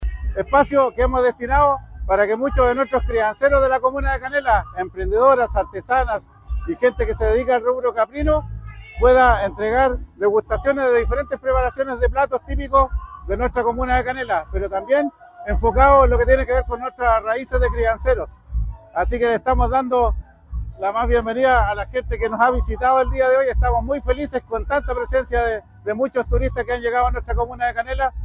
10a-Fiesta-Cabrito-Canela-Cuna-02-Waldo-Contreras-Alcalde-Canela.mp3